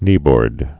(nēbôrd)